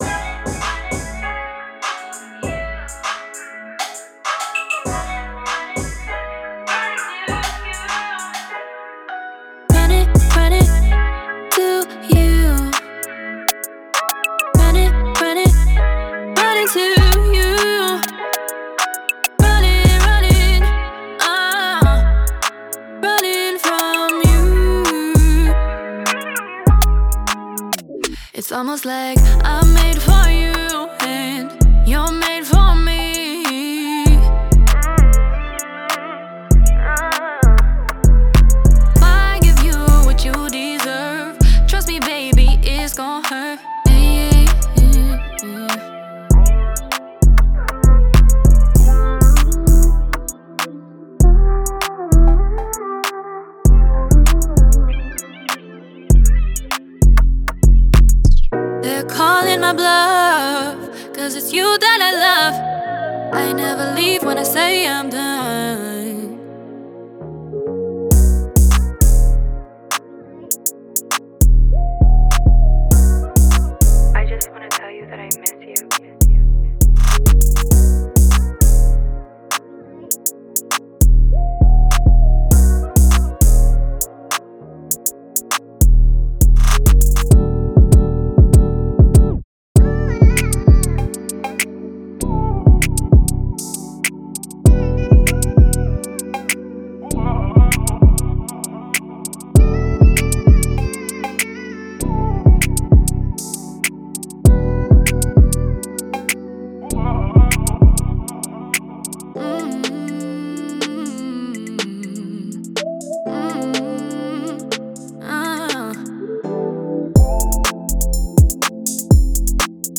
Genre:Soul and RnB